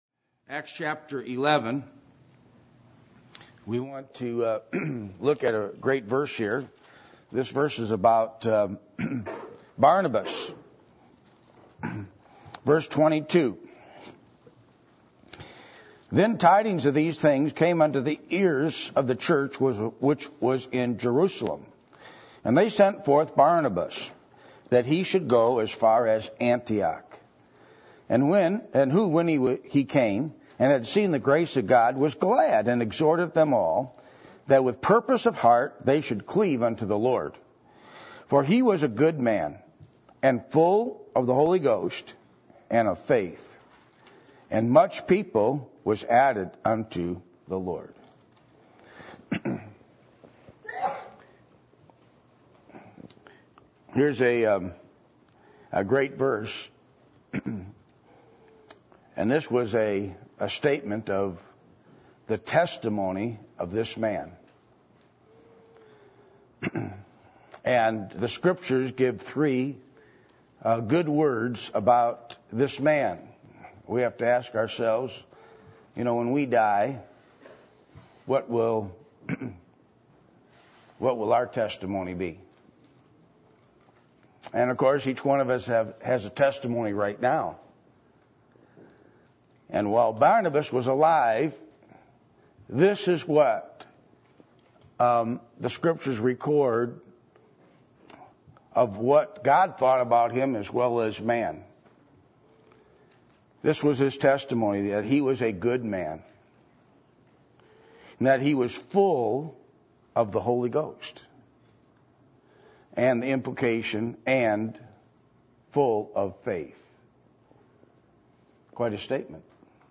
Acts 6:8 Service Type: Midweek Meeting %todo_render% « The Bible Law Of Separation What Is The Christian Life?